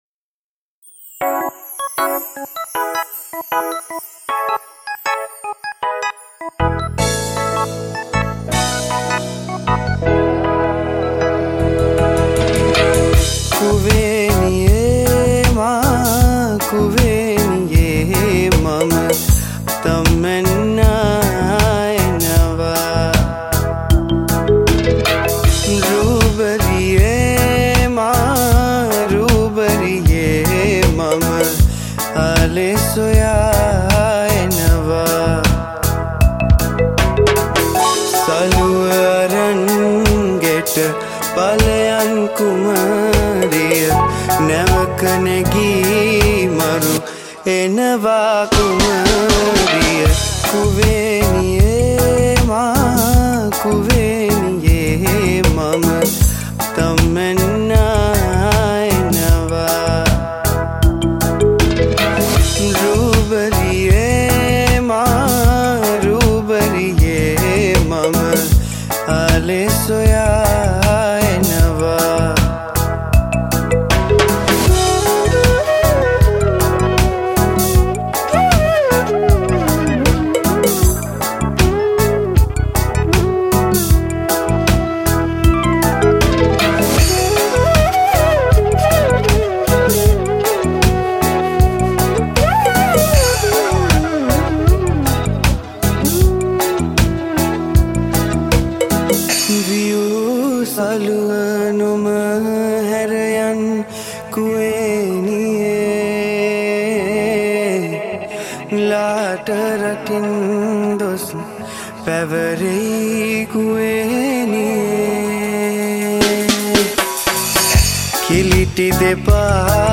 Reagge Remix
Reggae Remix